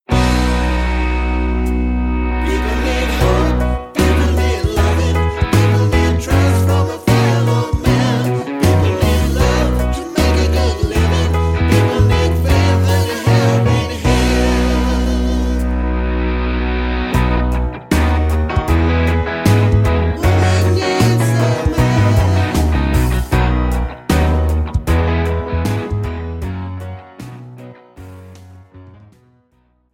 This is an instrumental backing track cover.
• Key – B
• Without Backing Vocals
• No Fade